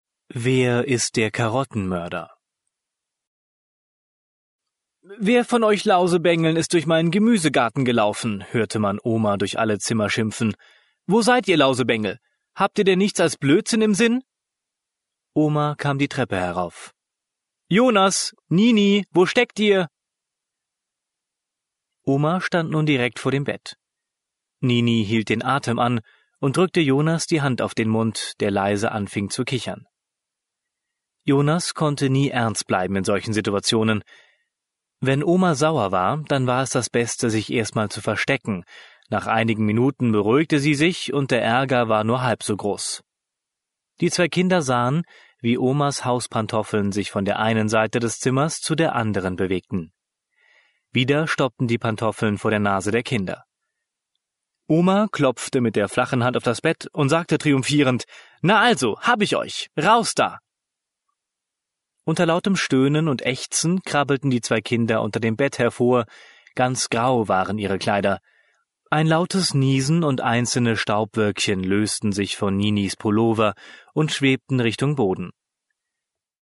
Alle Geschichten sind von deutschen Muttersprachlern gesprochen, um den Kindern ein Gefühl für die Sprachmelodie und Aussprache zu vermitteln.
Die Audio-CD Deutsche Kindergeschichten, Gisela das Drachenmädchen enthält 10 deutsche Kindergeschichten und richtet sich an alle Eltern, die ihre Kinder frühzeitig mit gutem und richtigem Deutsch vertraut machen möchten und die ihren Kindern die Möglichkeit geben möchten ihre sprachlichen Fähigkeiten so früh wie möglich zu entwickeln. Alle Geschichten sind von ausgebildeten Sprechern gesprochen, um den Kindern ein Gefühl für die Sprachmelodie und Aussprache des Hochdeutschen zu vermitteln.